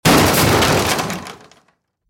Car Crash Sound Effect: Unblocked Meme Soundboard